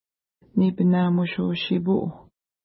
Image Not Available ID: 83 Longitude: -60.2908 Latitude: 52.6539 Pronunciation: ni:pəna:muʃu:-ʃi:pu: Translation: River With Places That Never Freeze Official Name: Little Drunken River Feature: river